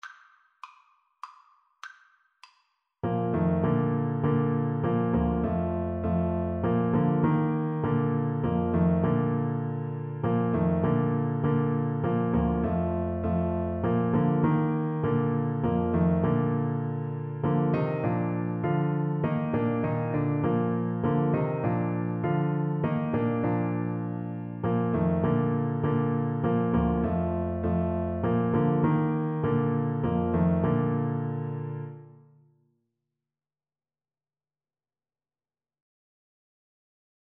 Alto Saxophone
3/4 (View more 3/4 Music)
Classical (View more Classical Saxophone Music)